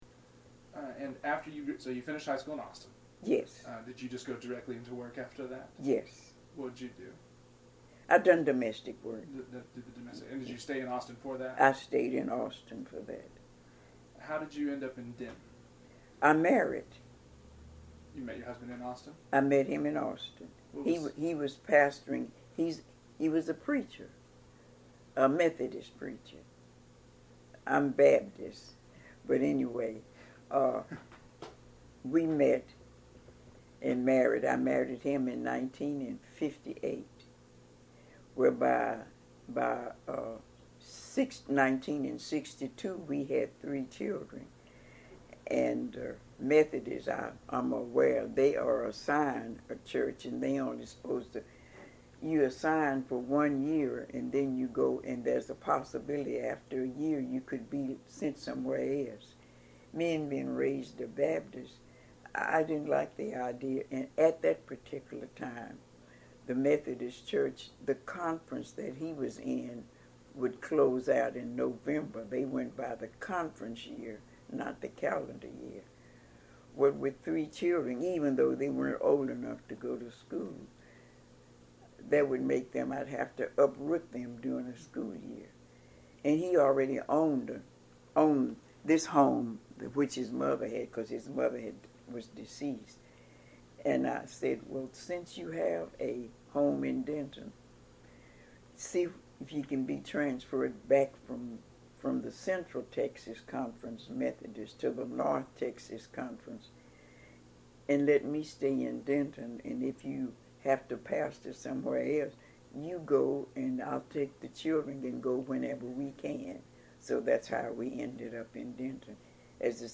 UNT Oral History Program